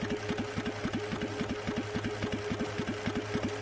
machine_idle_empty_01.ogg